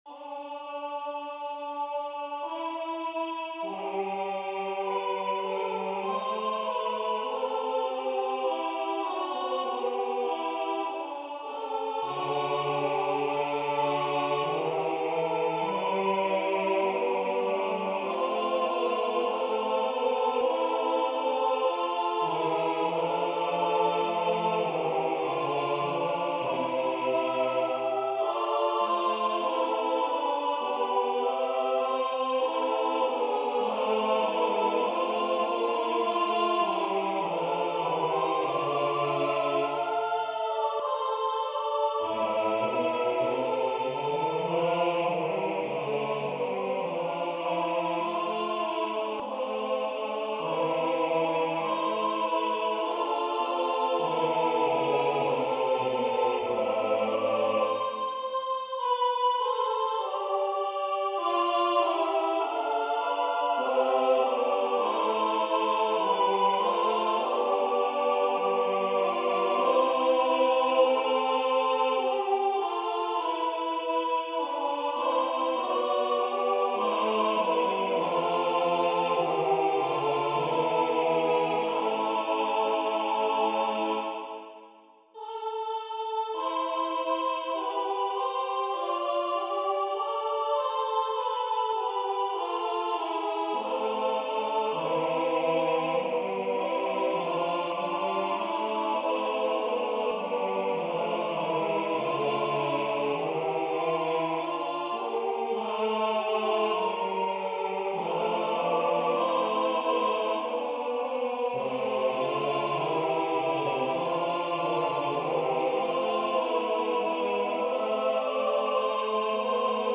A computer generated sound file is also attached.
Voicing/Instrumentation: SATB